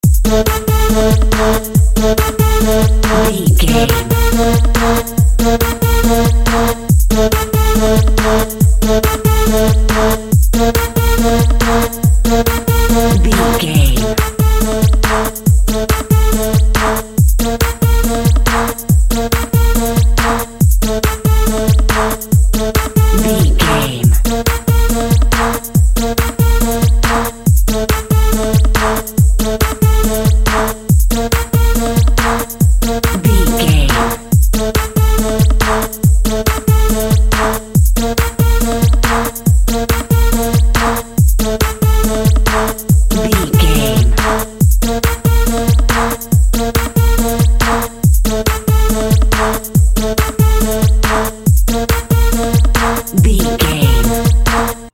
Epic / Action
Fast paced
Phrygian
aggressive
dark
groovy
futuristic
driving
energetic
drum machine
synthesiser
synth lead
synth bass
synth drums